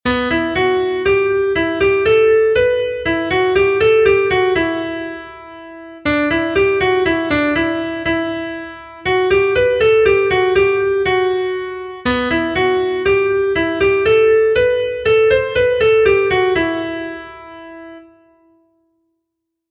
Gregorianoa
Seiko ertaina (hg) / Hiru puntuko ertaina (ip)
A-B-A2